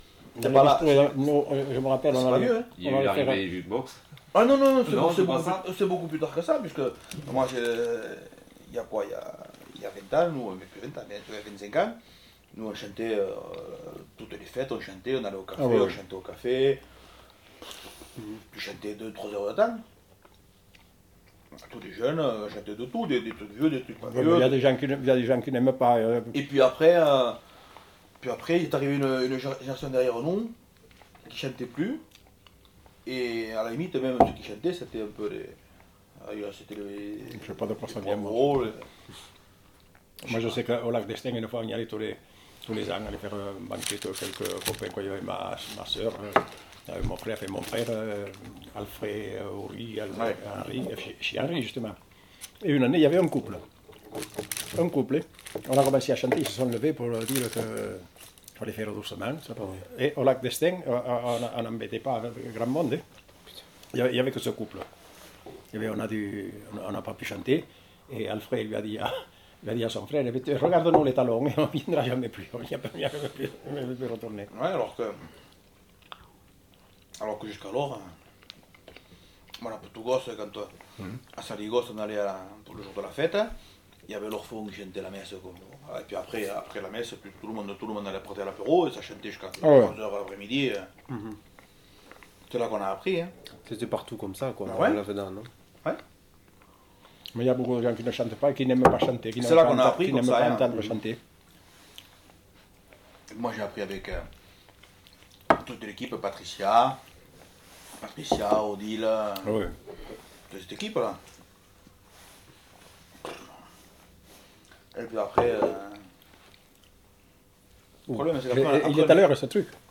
Aire culturelle : Bigorre
Lieu : Villelongue
Genre : témoignage thématique